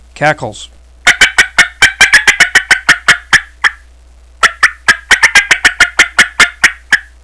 Listen to 7 seconds of cackles
yythreetwocackles7.wav